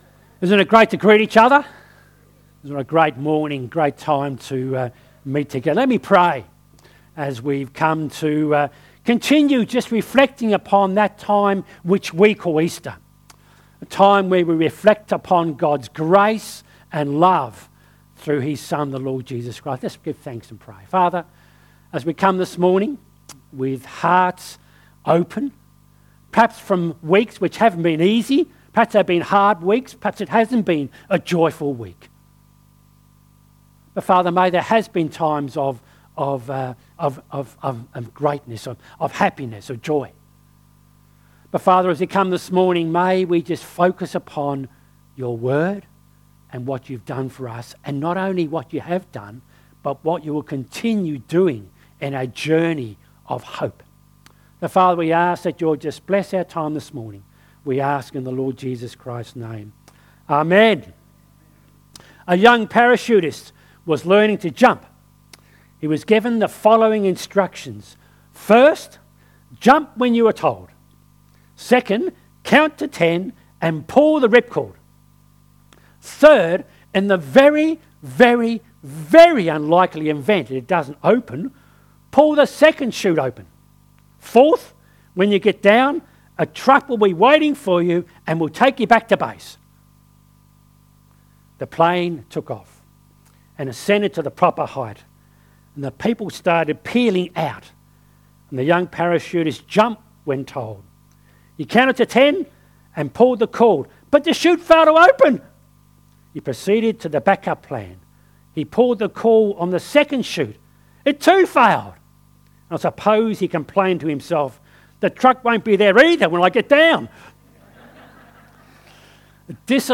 Lilydale Baptist Church 27th April, 10am service Sermon “The Journey Of Hope”